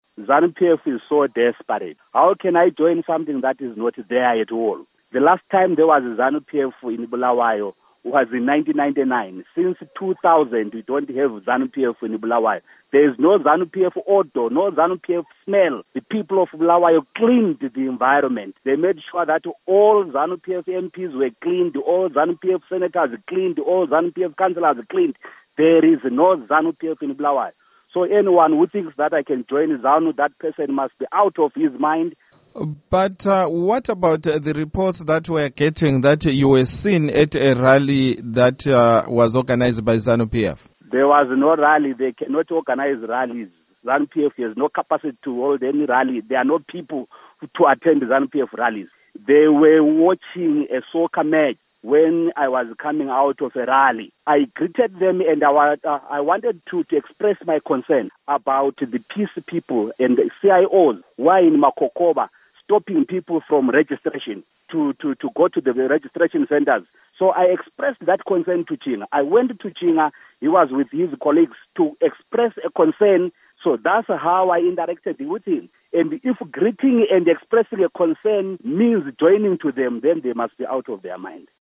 Interview with Gorden Moyo